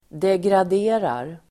Uttal: [degrad'e:rar]